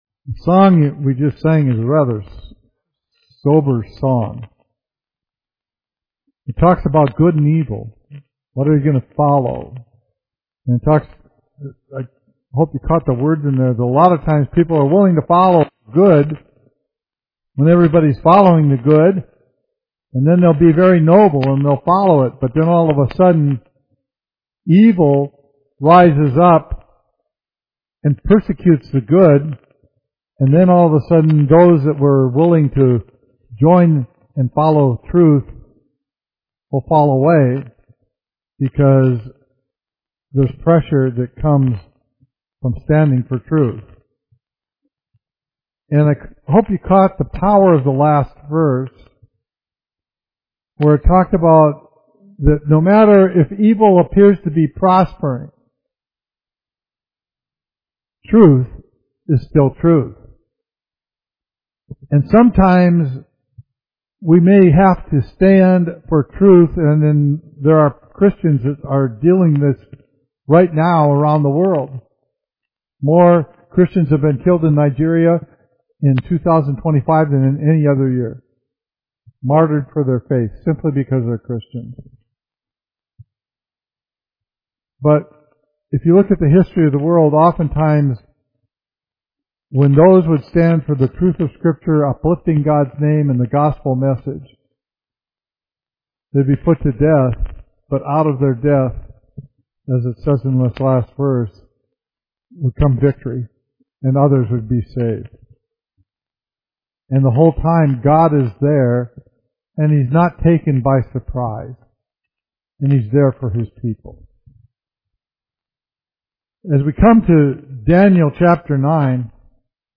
Study in Daniel